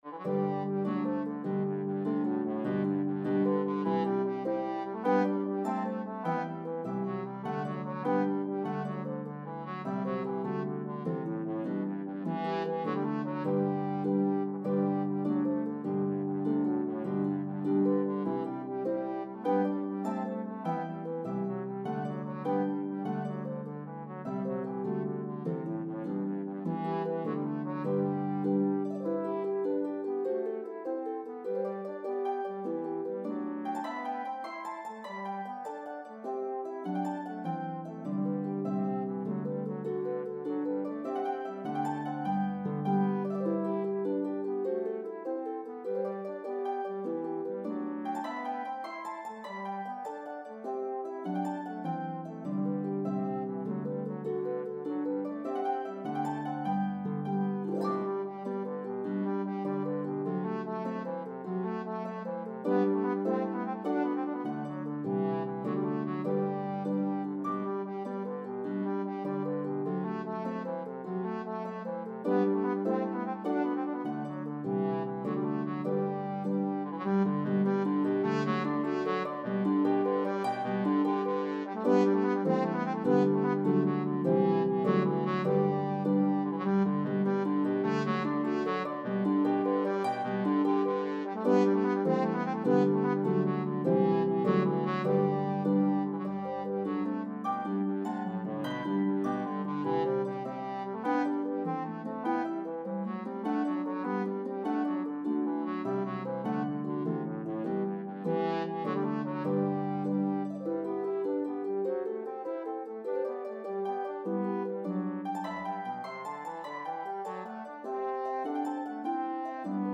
A spirited Irish Jig
The Harp part is playable on either Lever or Pedal Harps.